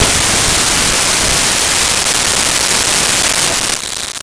Explosion Medium 2
Explosion_Medium_2.wav